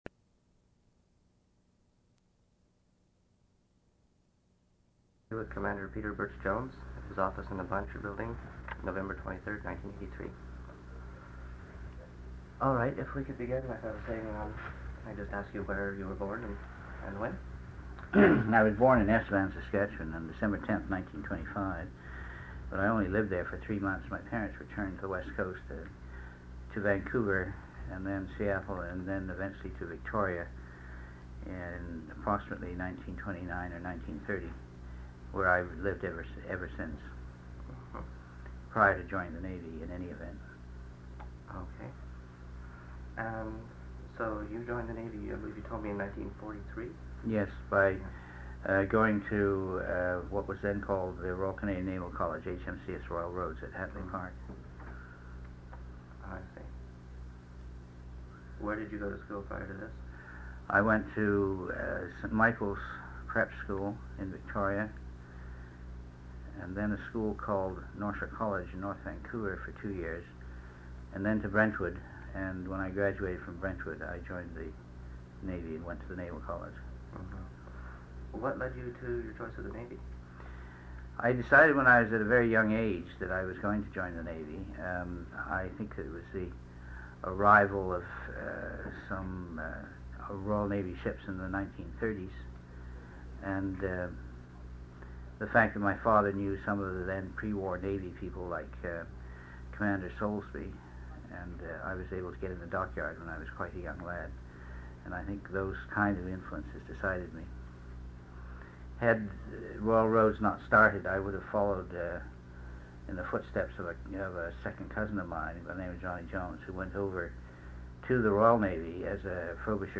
An interview/narrative